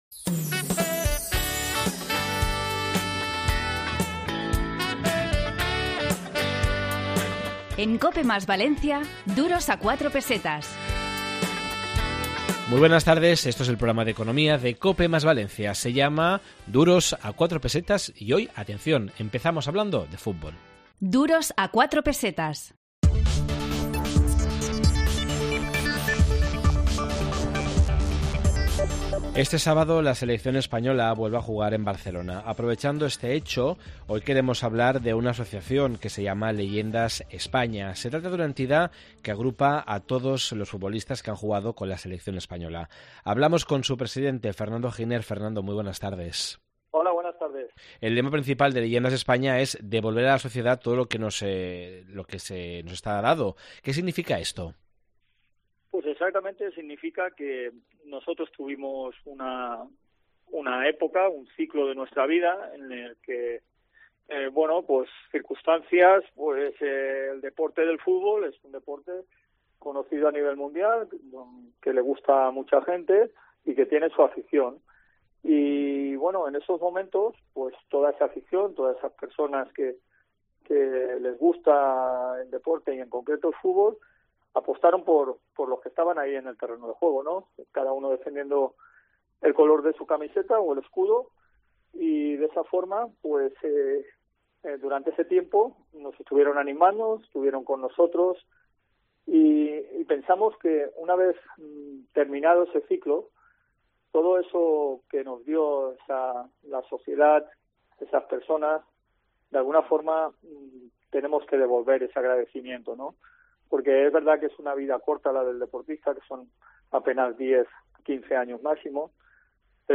Hemos entrevistado a Fernando Giner, presidente de la Asociación Española de Futbolistas Internacionales, también conocida como Leyendas España.